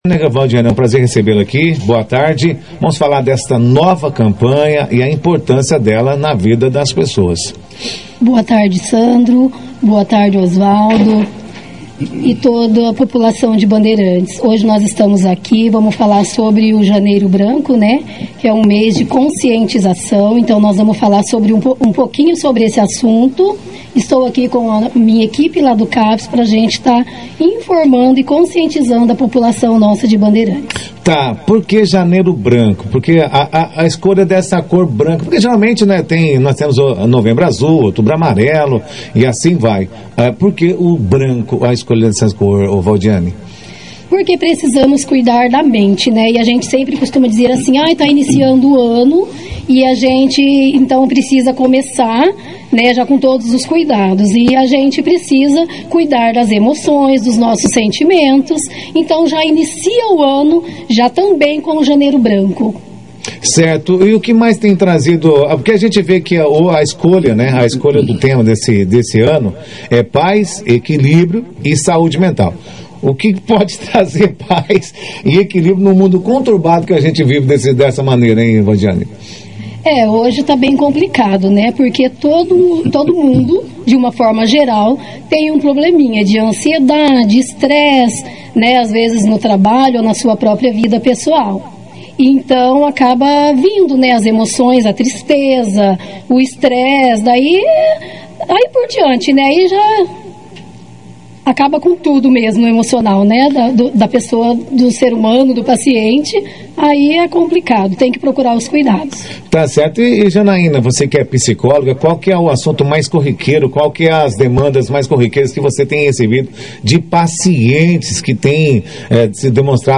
Na 2ª edição do Jornal Operação Cidade desta sexta-feira